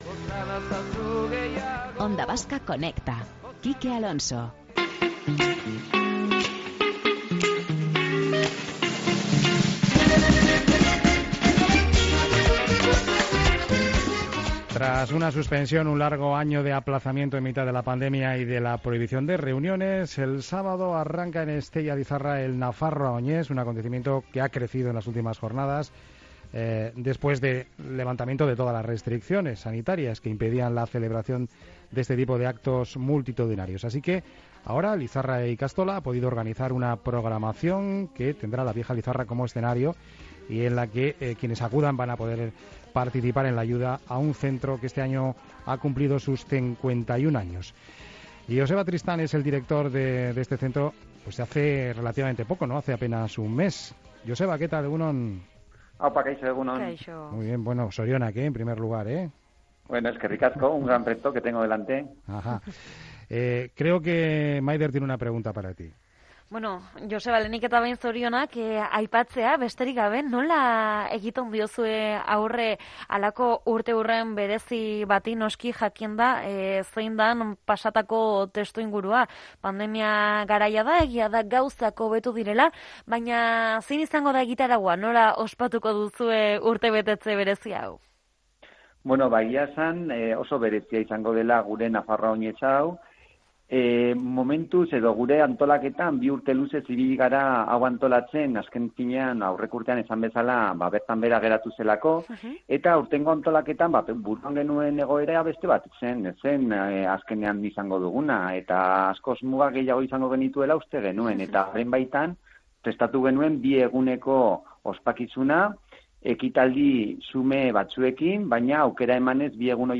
entrevistado en Onda Vasca